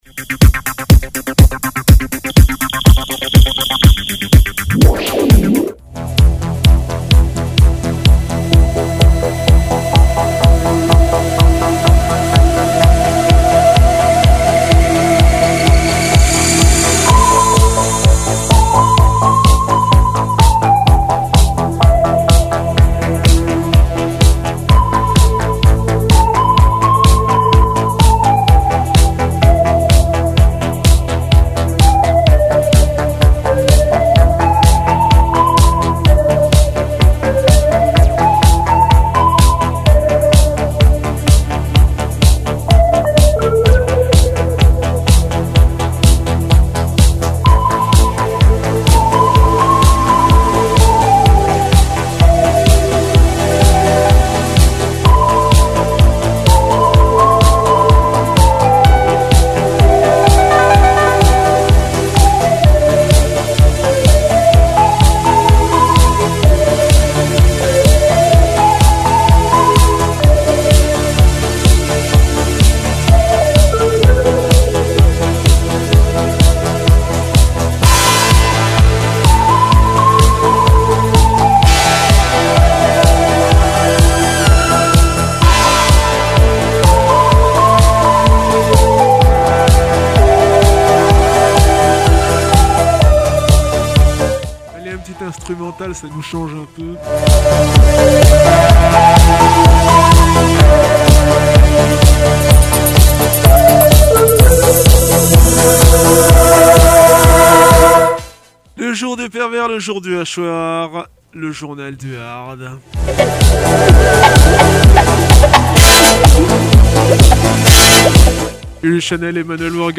Du death, encore du death, toujours du death (metal)